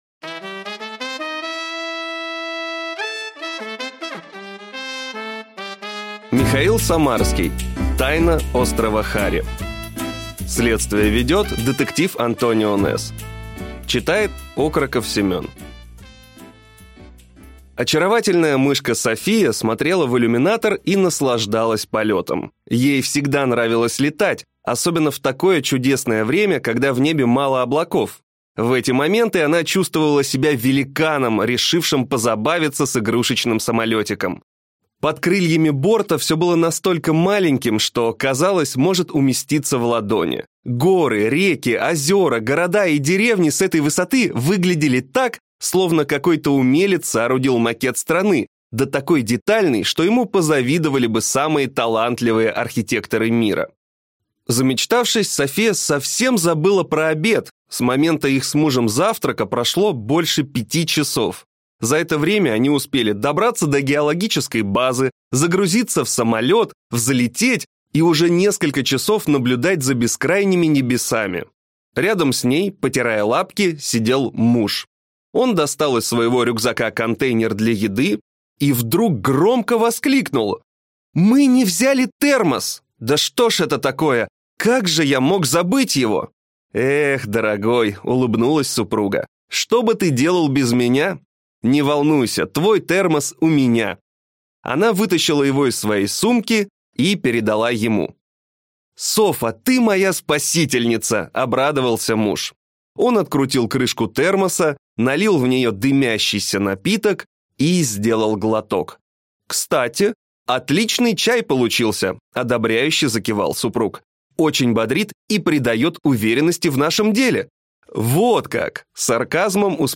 Аудиокнига Тайна острова Хари. Следствие ведёт детектив Антонио Нэсс | Библиотека аудиокниг